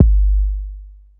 SouthSide Kick Edited (54).wav